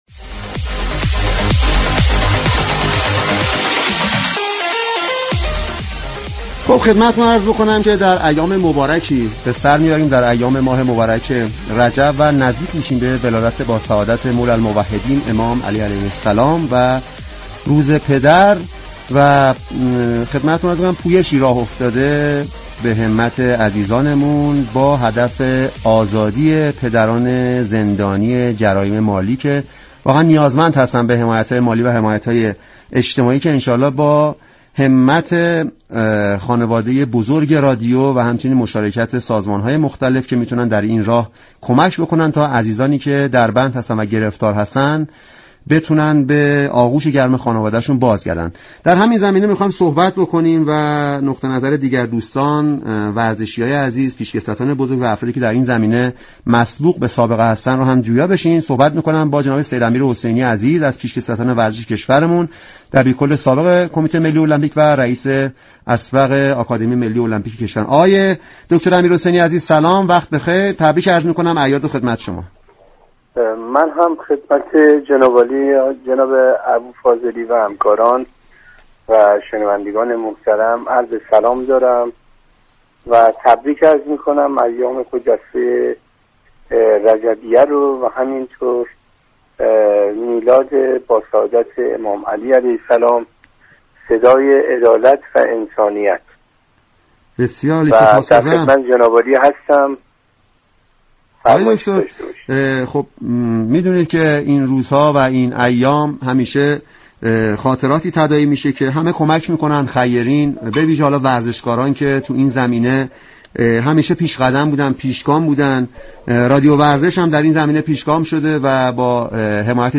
سید امیر حسینی، دبیركل سابق كمیته المپیك در برنامه «تازه ها» چهارم اسفند در خصوص پویش «به عشق علی، برای پدر» به گفتگو پرداخت.